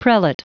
Prononciation du mot prelate en anglais (fichier audio)
Prononciation du mot : prelate